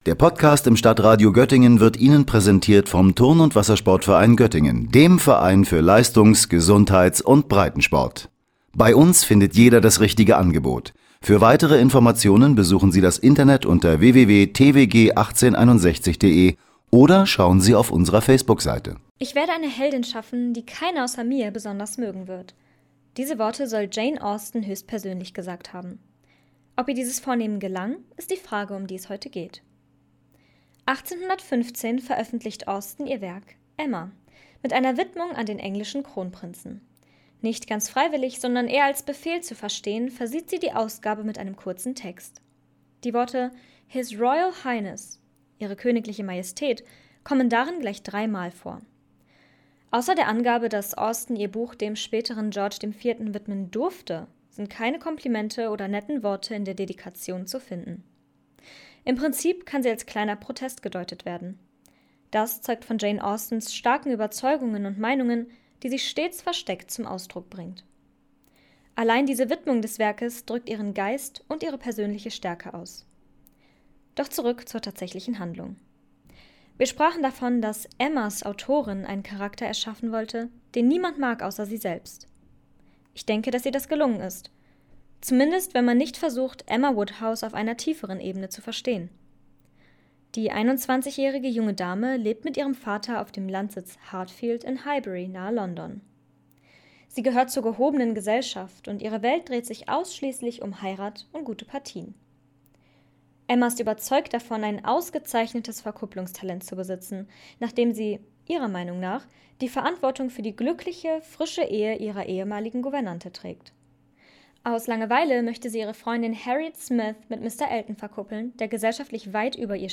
Beiträge > Rezension: „Emma“ von Jane Austen - StadtRadio Göttingen